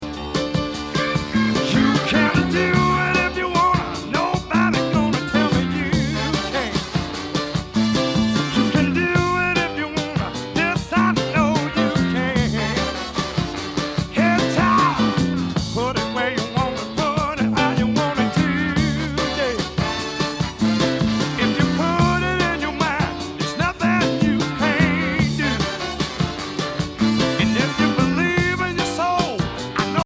Rhythm & Blues